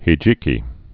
(hē-jēkē)